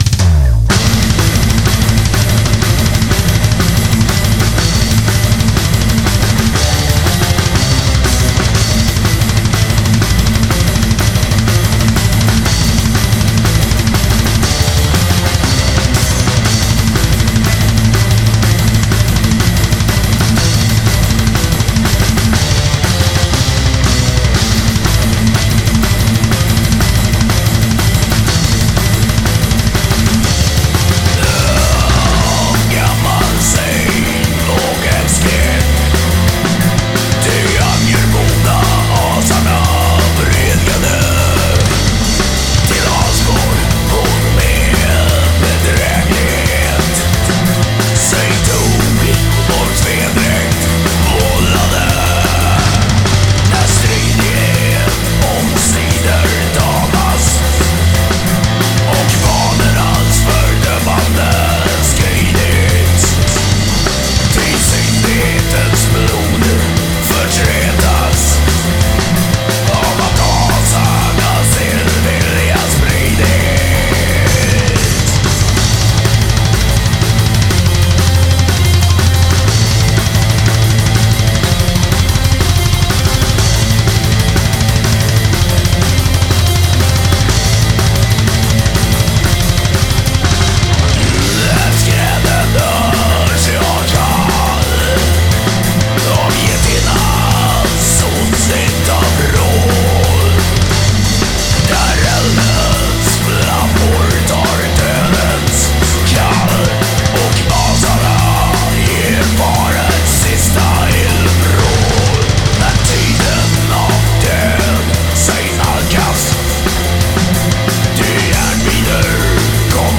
Black & Pagan Metal